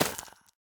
Minecraft Version Minecraft Version snapshot Latest Release | Latest Snapshot snapshot / assets / minecraft / sounds / block / roots / break3.ogg Compare With Compare With Latest Release | Latest Snapshot
break3.ogg